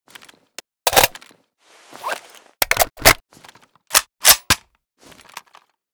l85_reload_empty.ogg.bak